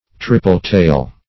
triple-tail - definition of triple-tail - synonyms, pronunciation, spelling from Free Dictionary
Triple-tail \Tri"ple-tail`\, n. (Zool.)